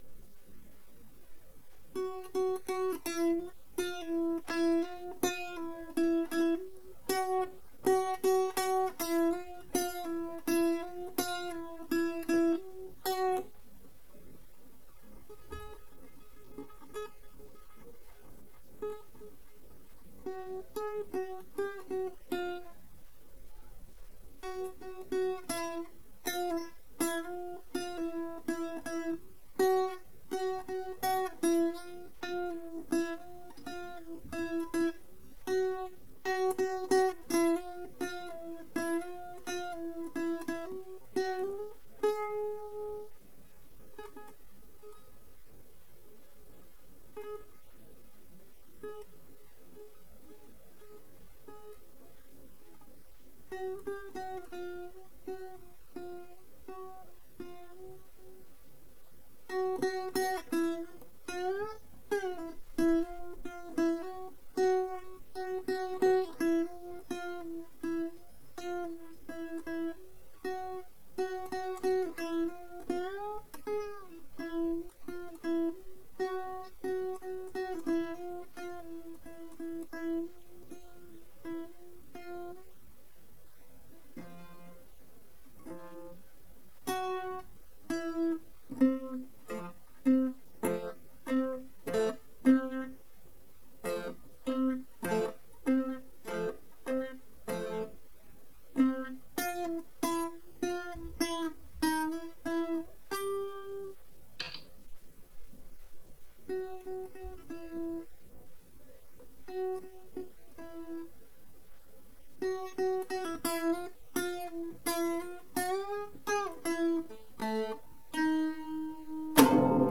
"Picusumus Strum" Guitar